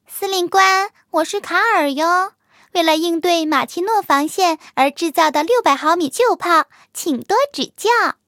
卡尔臼炮登场语音.OGG